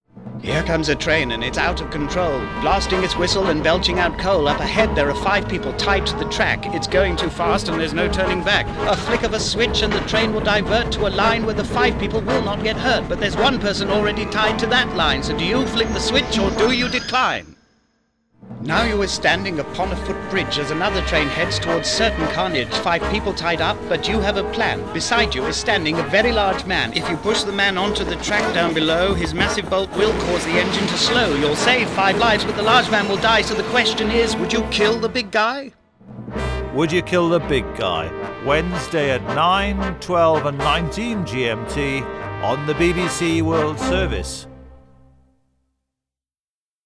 It’s a very good radio promo: